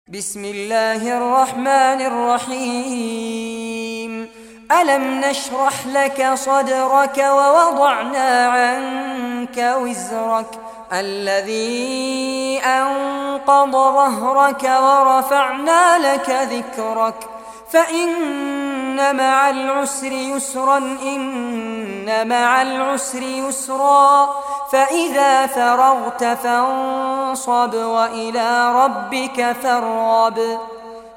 Surah Inshirah Recitation by Fares Abbad
Surah Inshirah, listen or play online mp3 tilawat / recitation in Arabic in the beautiful voice of Sheikh Fares Abbad.